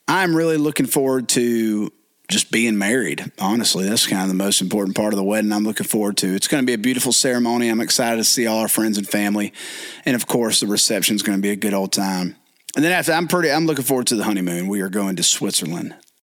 Audio / Travis Denning says he is looking forward to being married.